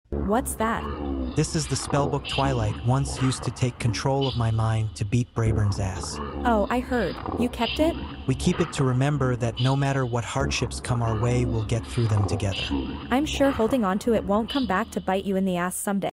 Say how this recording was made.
(630kB / 0:00:16 / 320kbps, 48kHz)creepy spellbook - no music.mp3 And if anyone else wants to try isolating it, here's the audio with the music removed but the voices intact